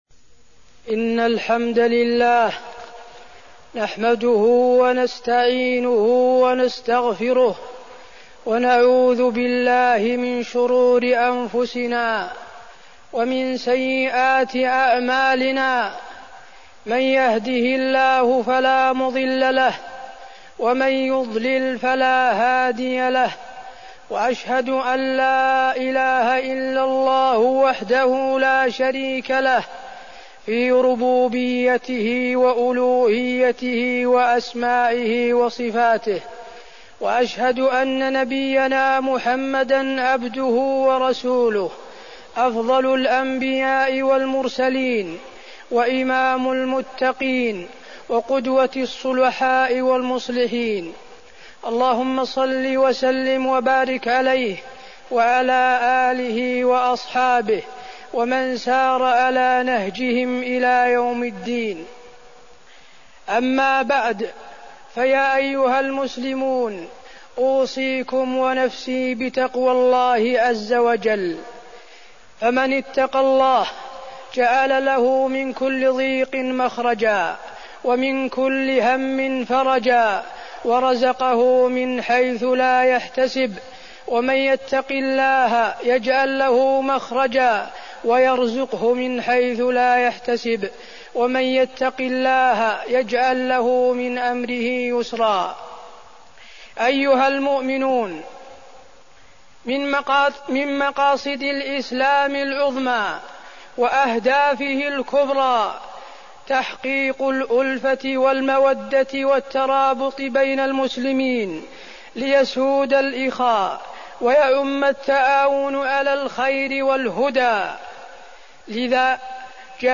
تاريخ النشر ١٧ رجب ١٤١٩ هـ المكان: المسجد النبوي الشيخ: فضيلة الشيخ د. حسين بن عبدالعزيز آل الشيخ فضيلة الشيخ د. حسين بن عبدالعزيز آل الشيخ الإصلاح بين الناس